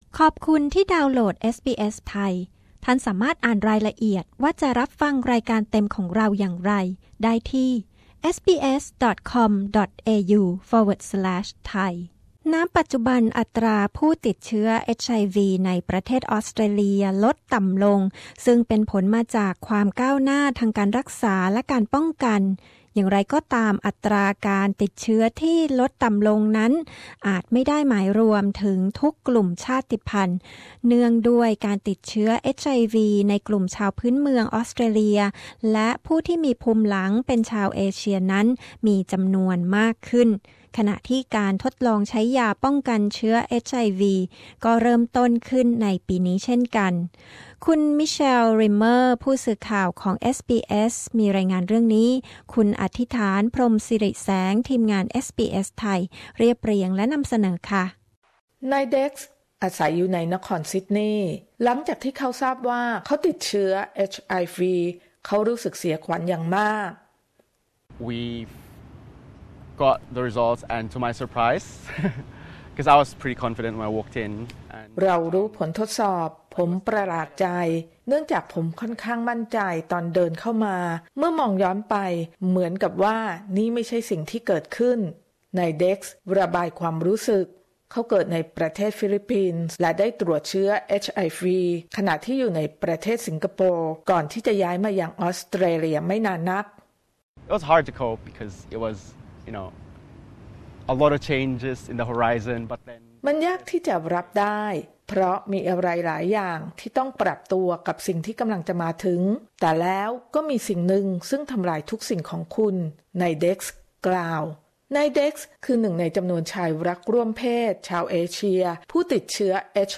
ขณะที่อัตราโดยรวมของผู้ติดเชื้อ HIV ในประเทศออสเตรเลียลดต่ำลง แต่การติดเชื้อ HIV ในกลุ่มชาวพื้นเมืองออสเตรเลียและผู้ที่มีภูมิหลังเป็นชาวเอเชียในออสเตรเลียนั้นกลับเพิ่มขึ้น อะไรคือสาเหตุ ติดตามได้ในรายงาน